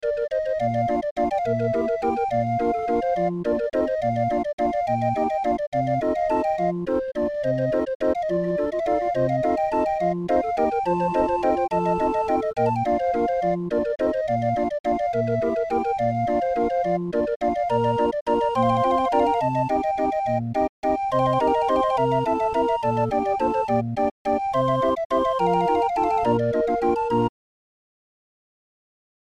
Musikrolle 31-er